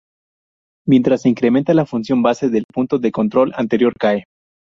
ca‧e
/ˈkae/